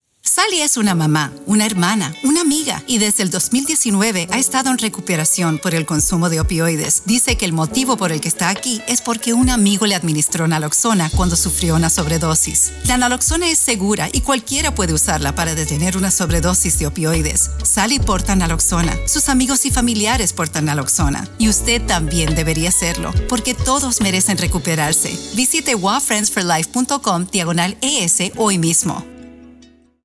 Carry Naloxone Audio PSA